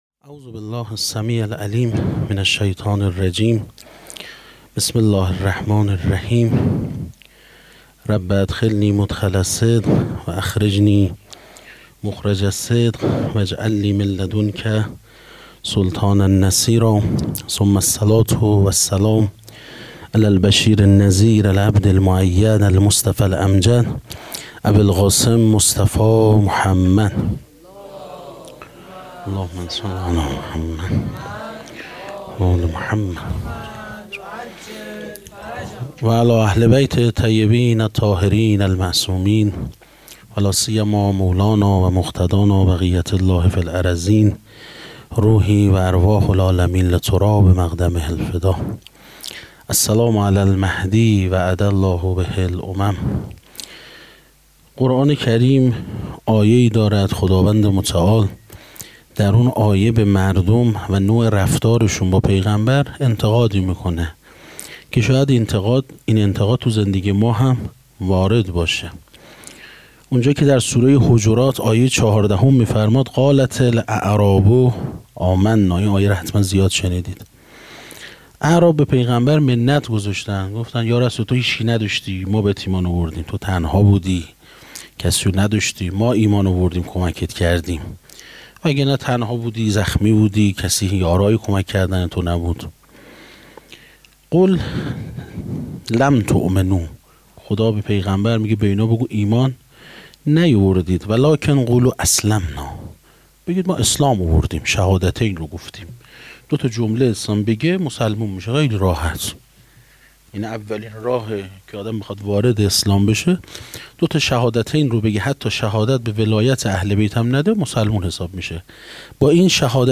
سخنرانی
حسینیه بیت النبی